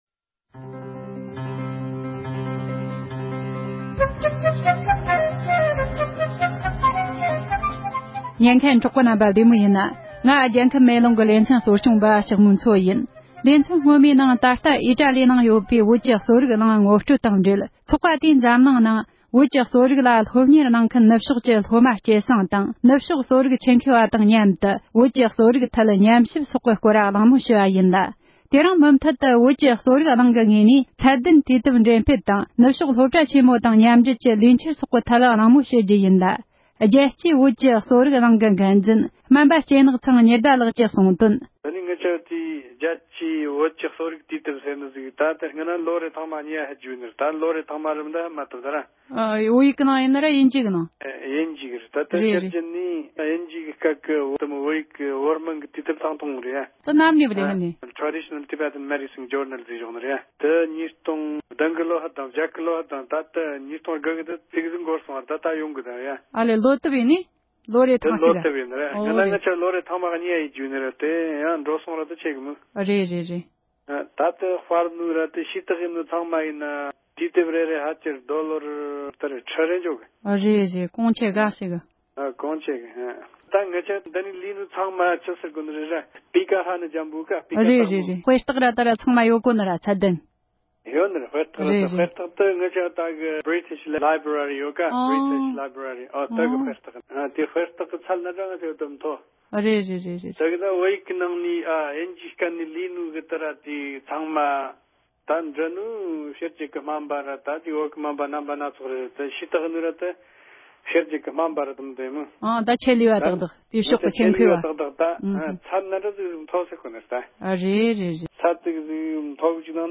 ཨོ་སི་ཊེ་ལི་ཡའི་ནང་ཡོད་པའི་རྒྱལ་སྤྱིའི་བོད་ཀྱི་གསོ་རིག་གླིང་གི་སྐོར་གླེལ་མོལ་ཞུས་པའི་ལེ་ཚན་གསུམ་པ།